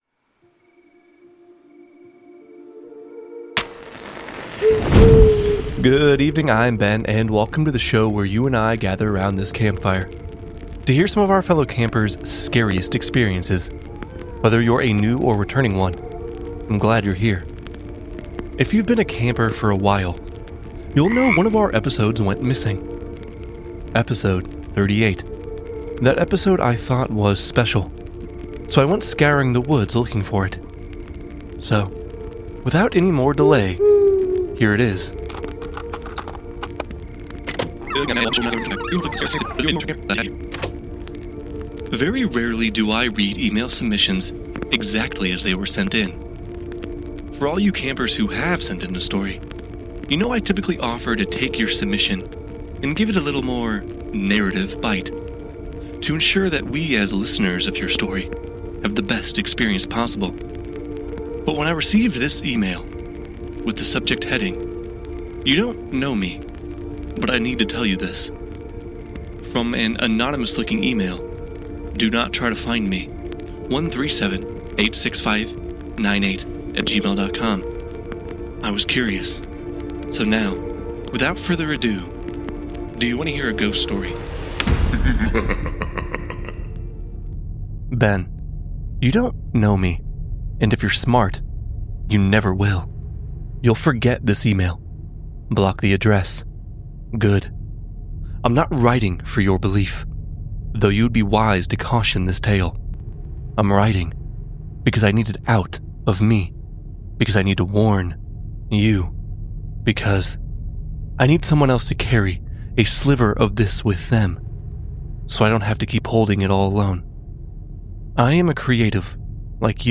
Tune in for a slow-burn, psychological horror story about ambition, identity, and the cost of creation.